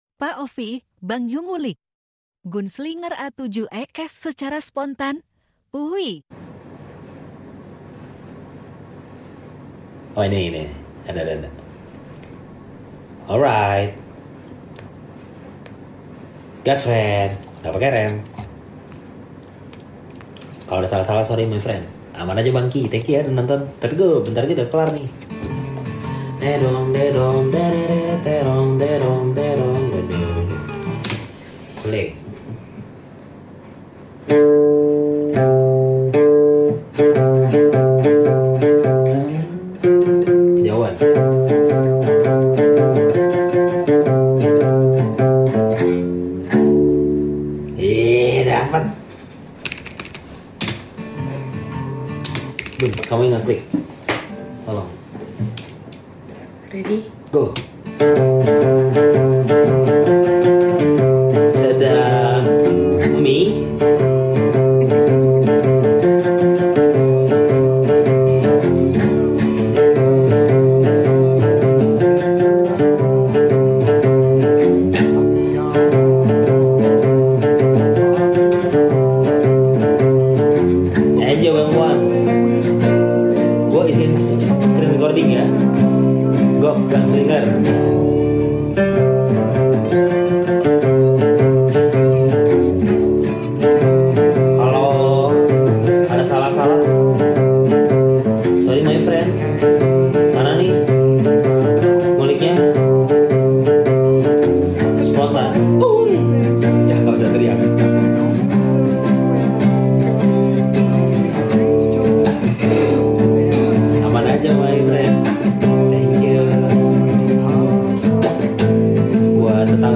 Versi Ngulik Spontan Pas LIVE.